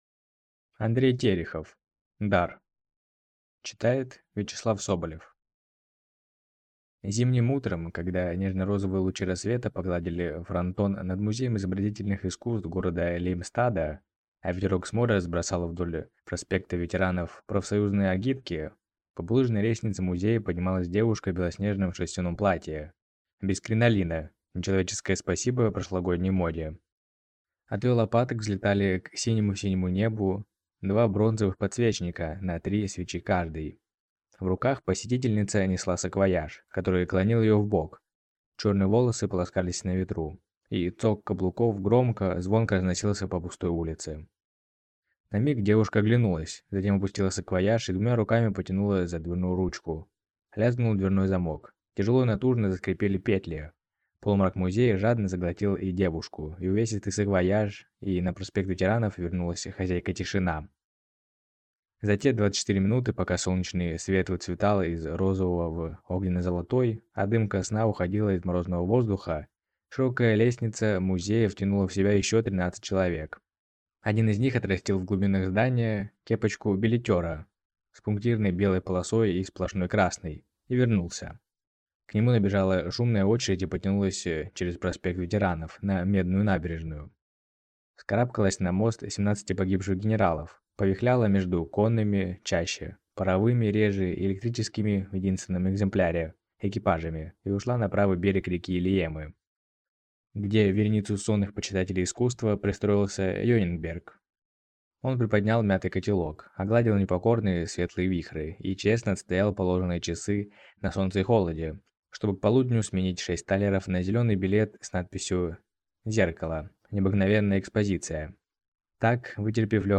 Аудиокнига Дар | Библиотека аудиокниг